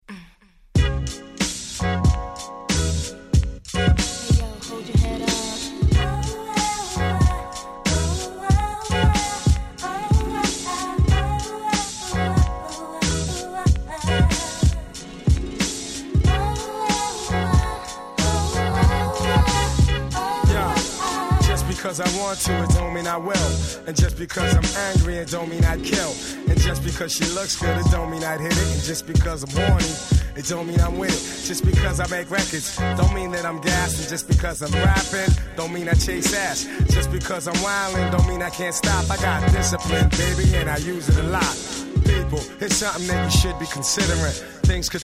99' Street Hit Hip Hop.
彼らの楽曲でサビに女性Vocalをfeat.している曲って実はめちゃくちゃ珍しいんですよ。